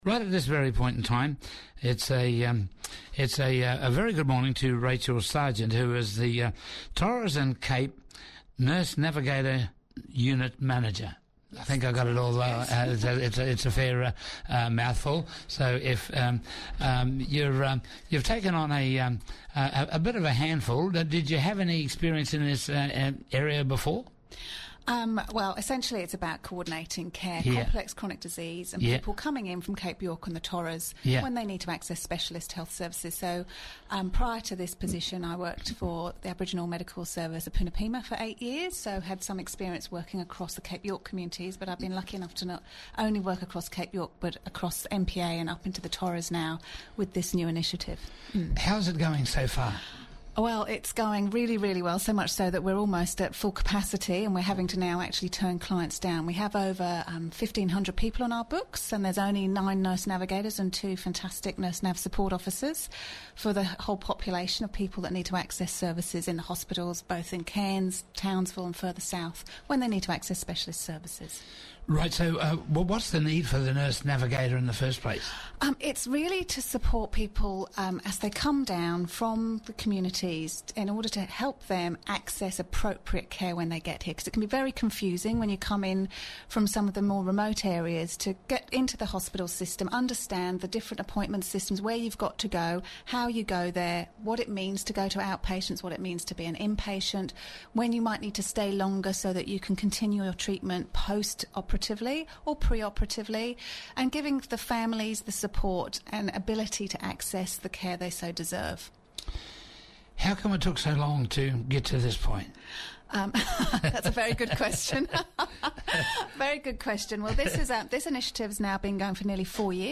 on Morning Focus said their job was to smooth a patients journey through the health system,she was joined in the studio